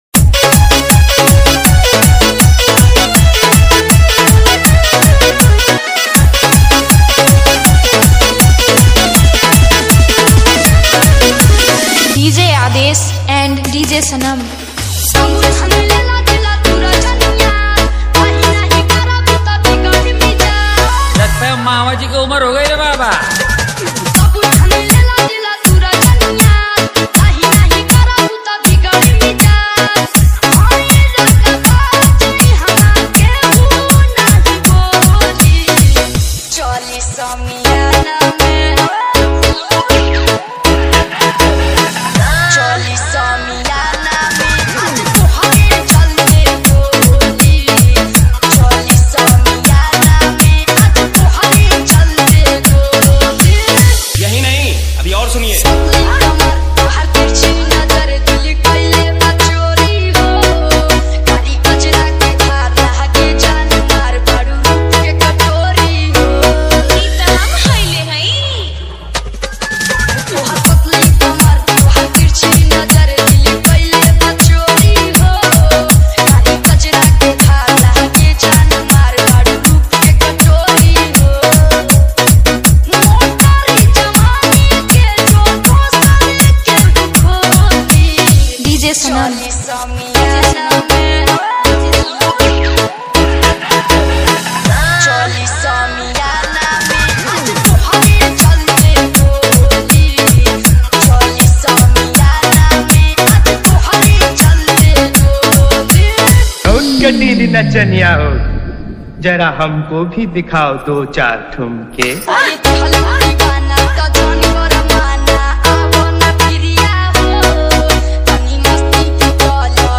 Bhojpuri Love DJ Remix